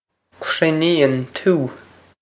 krin-een too
This is an approximate phonetic pronunciation of the phrase.